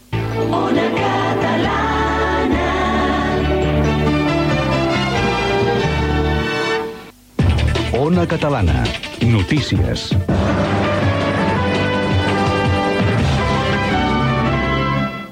Indicatiu de la cadena i careta de le notícies